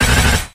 0904f27afa2d88df40a26451bcec0d83741fab2e infinitefusion-e18 / Audio / SE / Cries / BELDUM.ogg infinitefusion d3662c3f10 update to latest 6.0 release 2023-11-12 21:45:07 -05:00 7.7 KiB Raw History